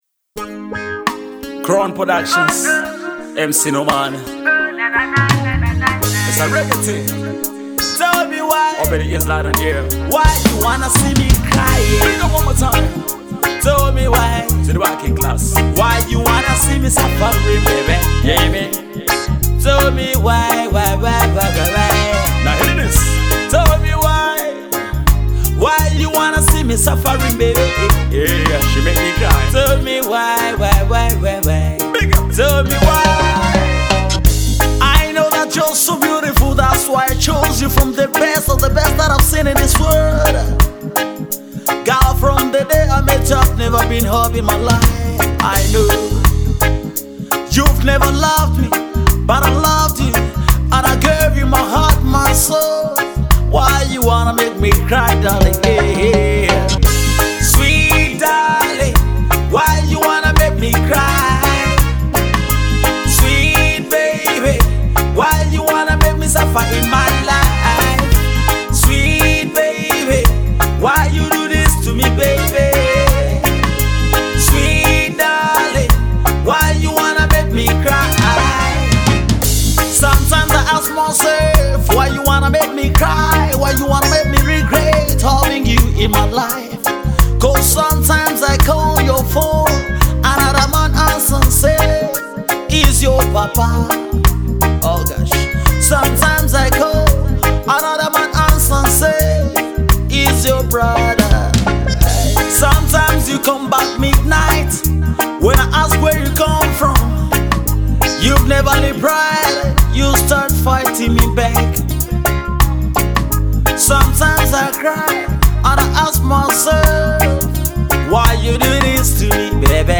A Reggae Music Journey and inspiration